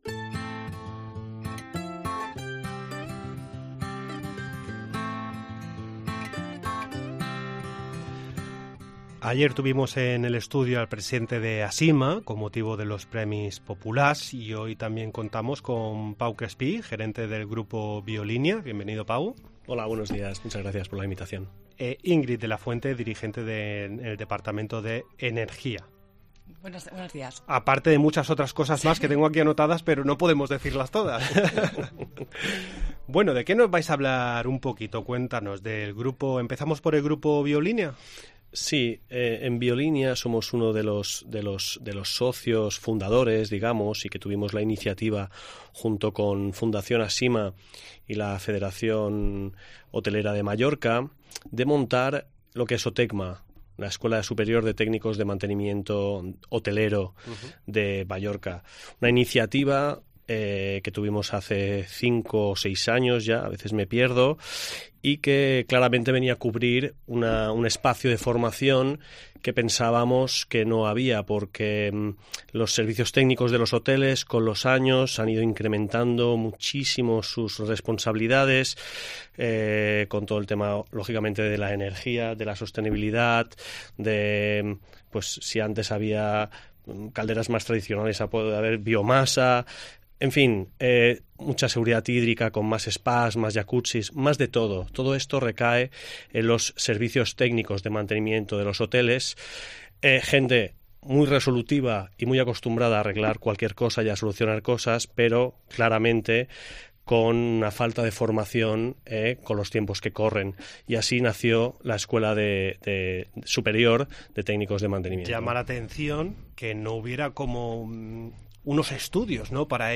Durante la entrevista, charlamos sobre la Escuela Superior de Formación, HOTECTA y también de la exposición Mujeres Ingenierass de éxito